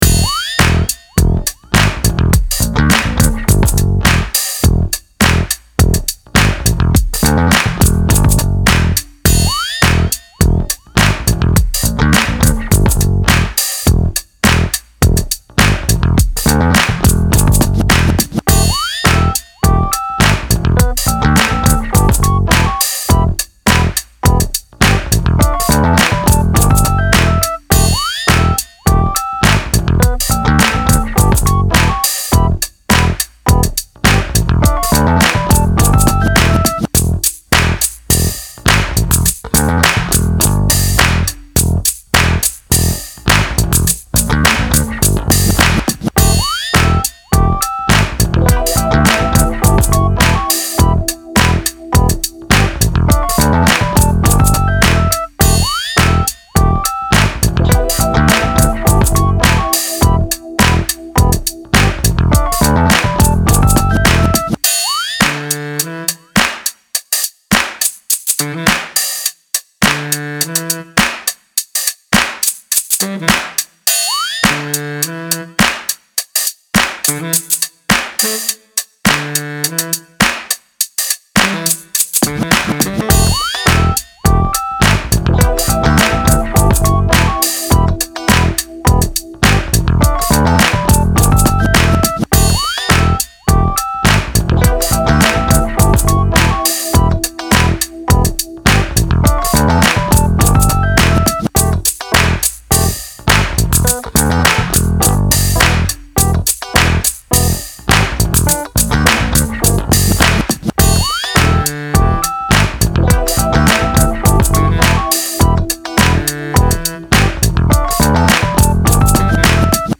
Style Style Hip-Hop, Pop
Mood Mood Cool, Relaxed
Featured Featured Bass, Claps/Snaps, Drums +3 more
BPM BPM 104